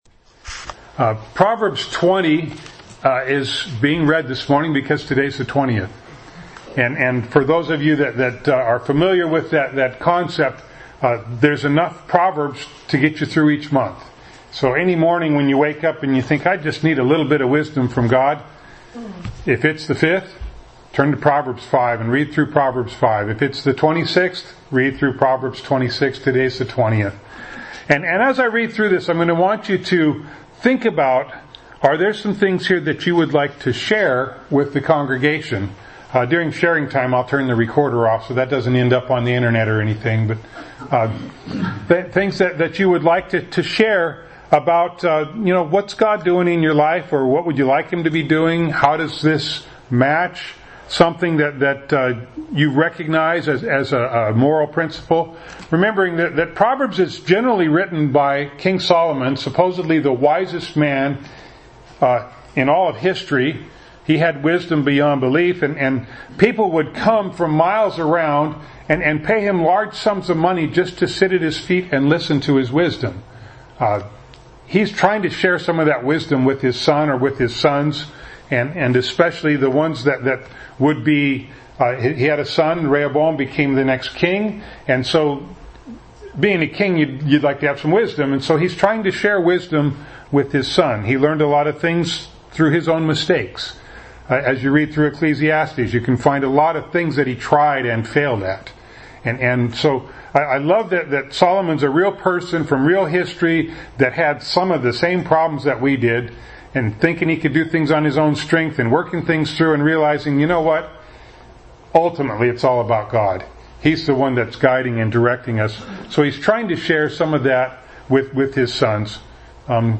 James 1:19 Service Type: Sunday Morning Bible Text